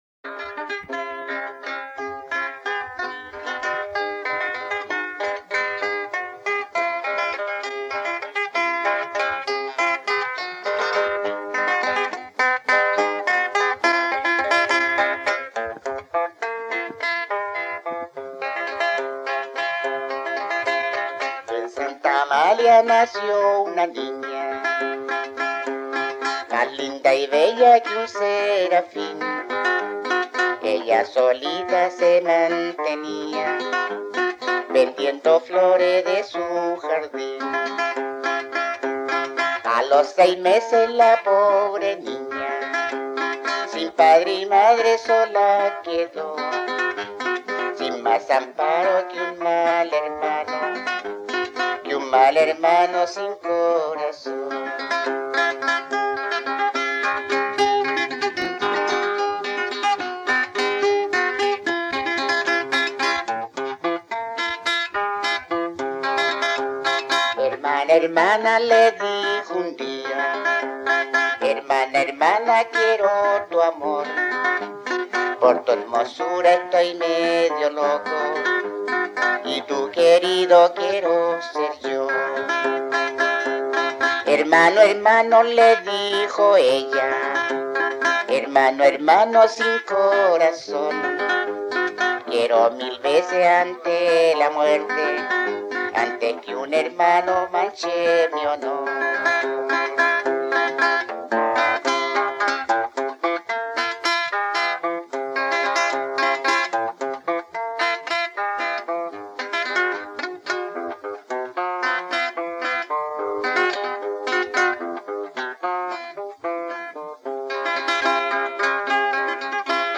Romance tradicional en forma de vals
Música tradicional
Folklore
Romance
Vals